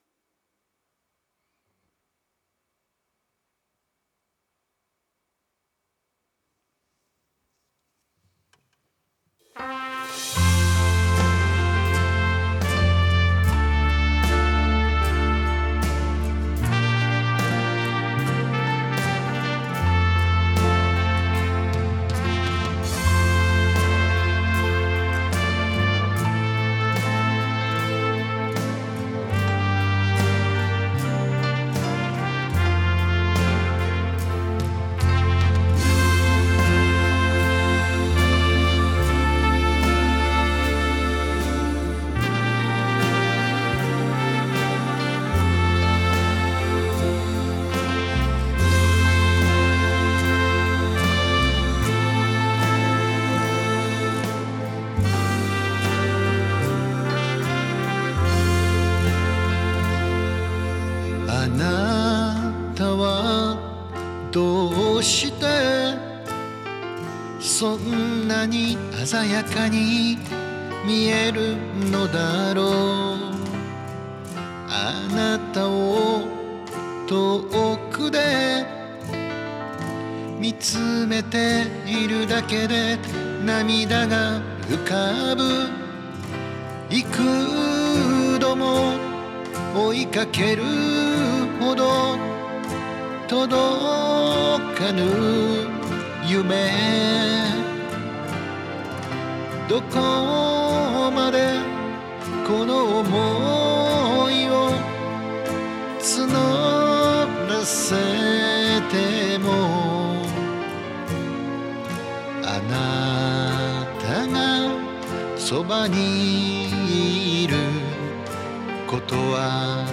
カバー曲　　青春時代の曲です